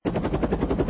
直升机_1.mp3